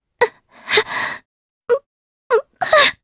Epis-Vox_Sad_jp.wav